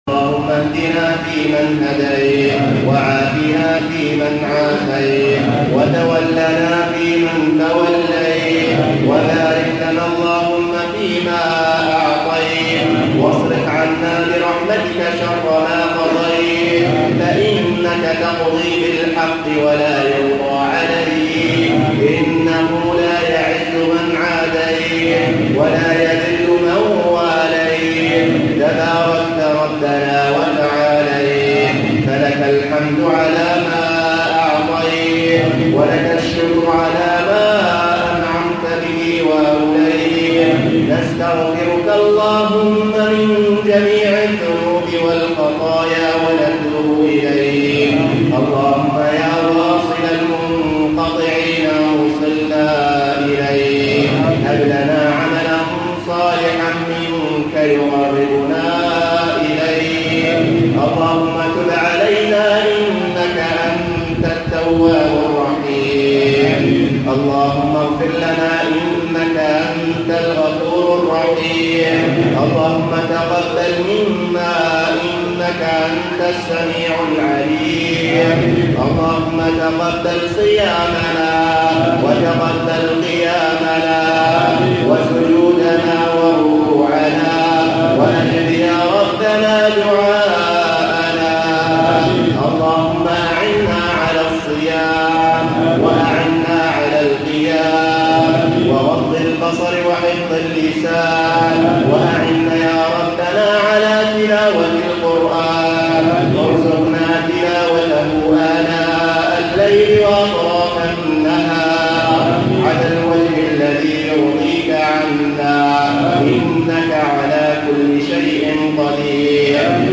دعاء القنوت
أدعية وأذكار